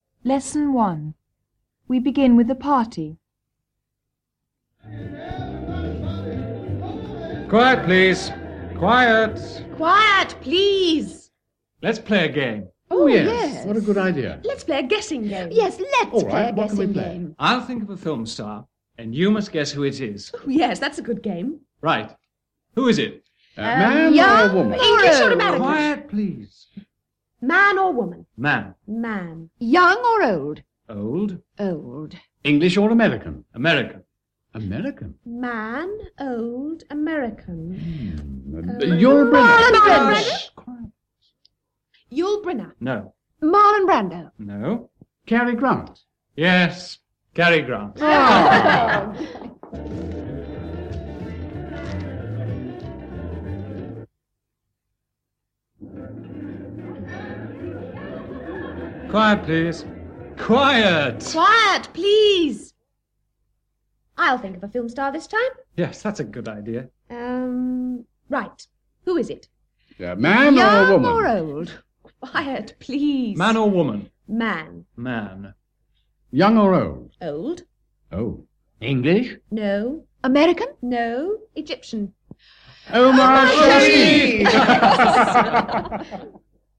Dialogo: Woman or Man? Young or Old?
Lesson one: